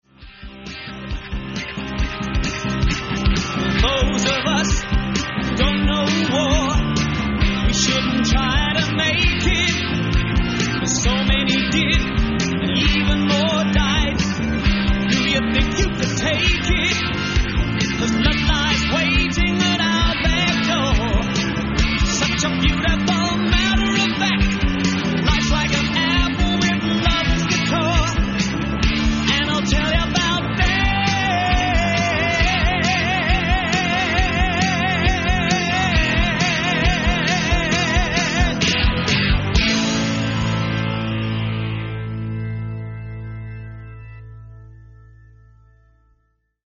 The Chateau D'Herouville, France